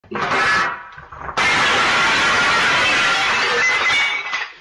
Loud Glass Breaking